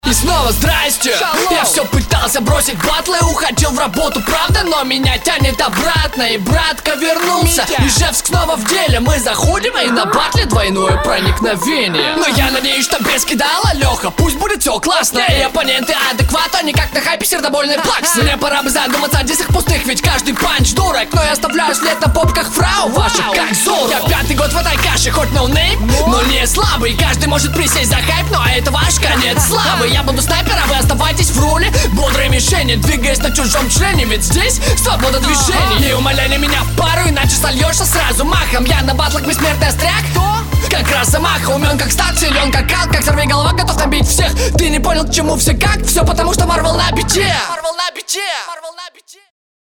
Ну совсем среднячок, текст простенький, паузы перед рифмами убивают, ничего интересного.
Кайфовая подача, хотелось бы другого подхода к тексту во втором